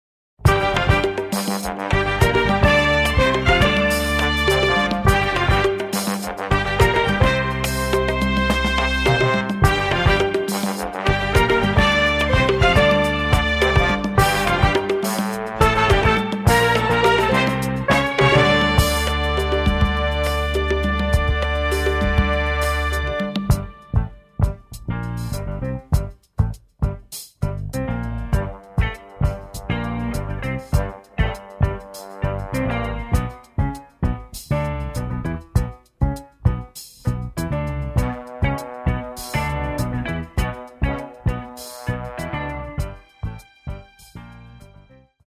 in the idioms of funk, jazz and R&B